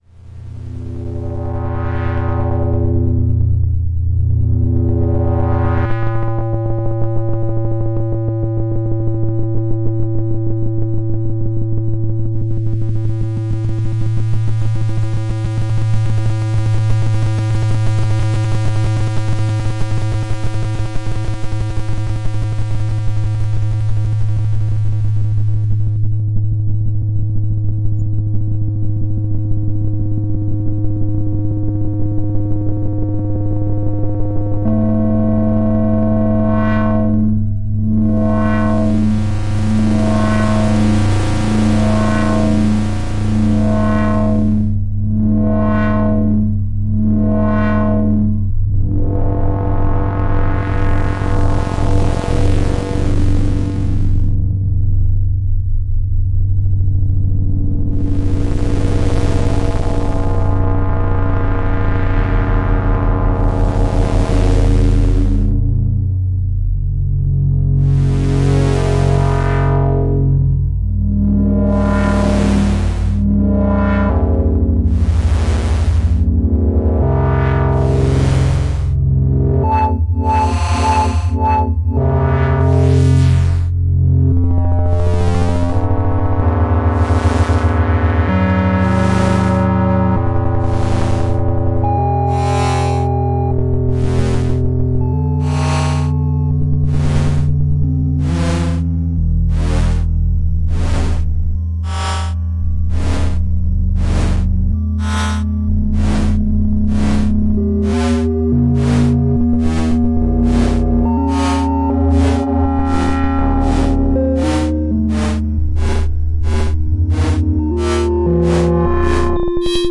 bitcrushed drone.
The sounds you hearare produced entirely by the plugins inside the loop with no original sound sources involved.